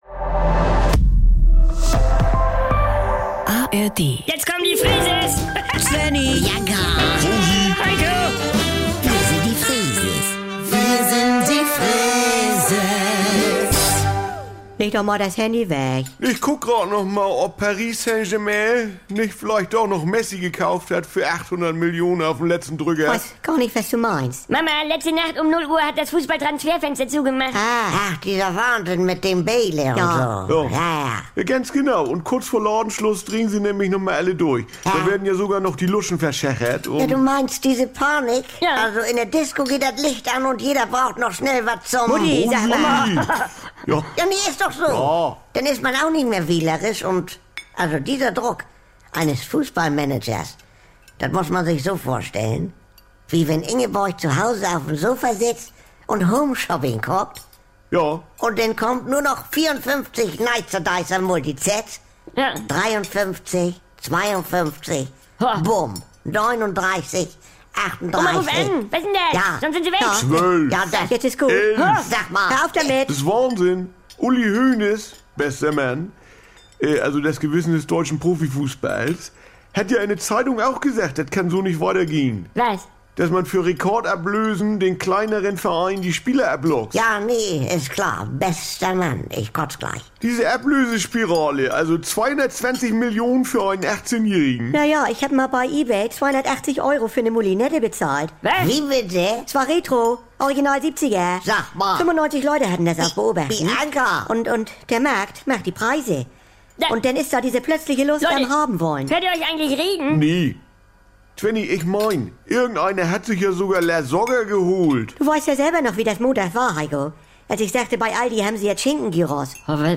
Jederzeit und so oft ihr wollt: Die NDR 2 Kult-Comedy direkt aus